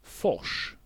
Ääntäminen
IPA : /ˈɡʌʃ/ US : IPA : [ˈɡʌʃ]